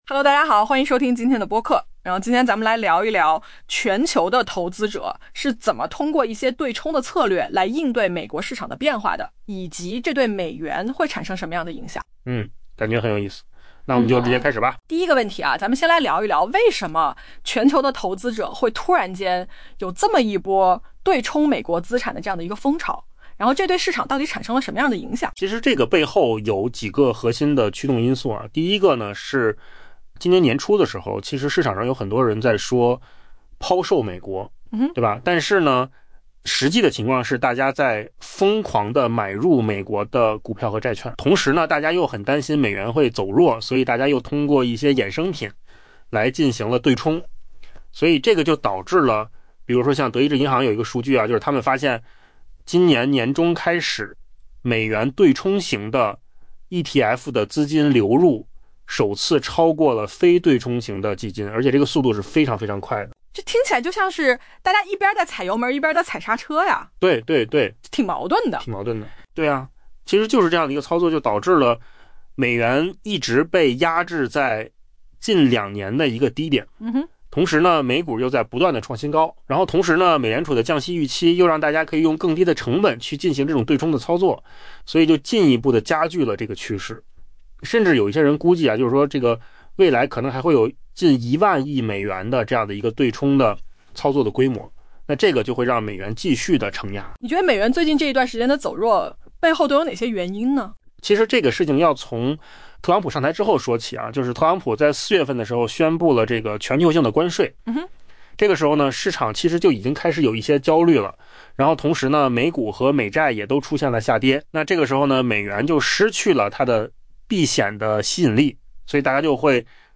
AI 播客：换个方式听新闻 下载 mp3 音频由扣子空间生成 事实证明，今年初弥漫市场的 「抛售美国」 焦虑完全错判了形势。